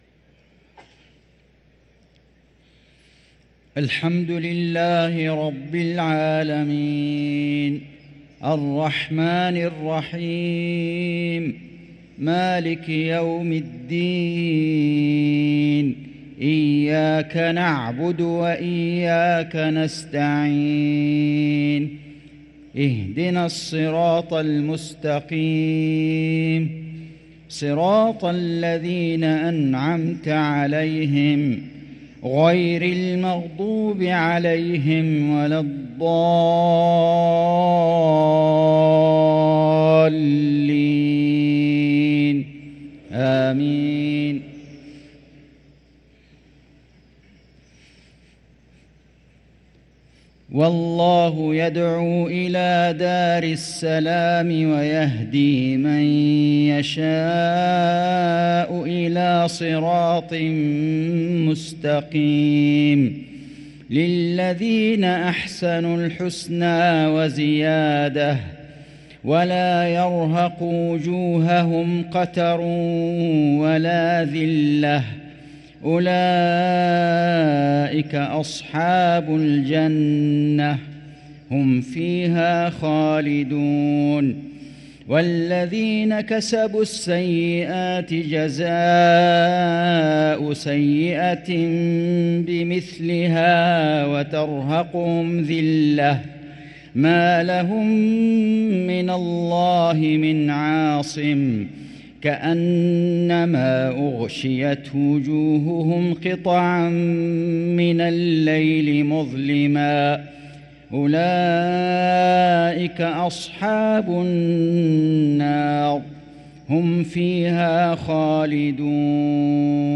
صلاة المغرب للقارئ فيصل غزاوي 3 رمضان 1444 هـ